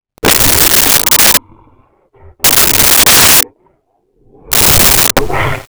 Creature Breath 04
Creature Breath 04.wav